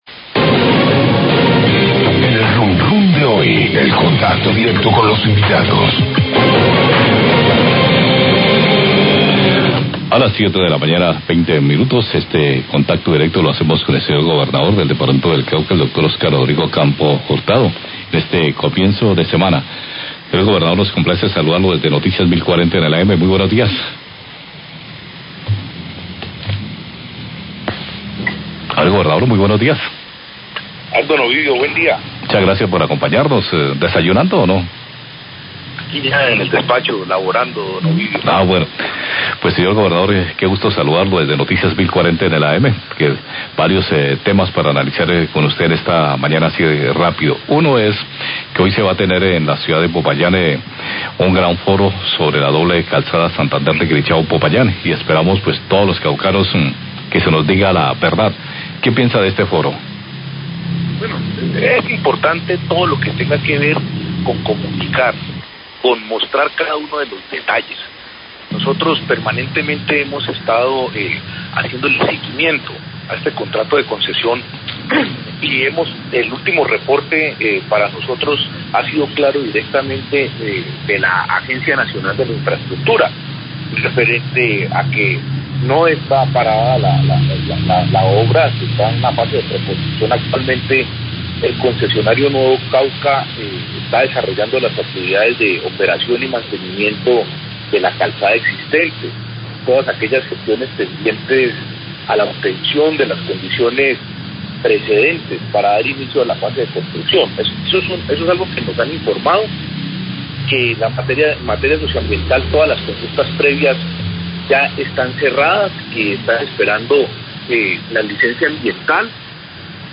Radio
El Gobernador del Cauca, Oscar Campo, habla de varios temas de interés para el departamento.